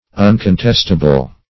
Uncontestable \Un`con*test"a*ble\, a.